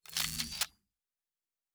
pgs/Assets/Audio/Sci-Fi Sounds/Weapons/Weapon 16 Reload 1 (Laser).wav at 7452e70b8c5ad2f7daae623e1a952eb18c9caab4
Weapon 16 Reload 1 (Laser).wav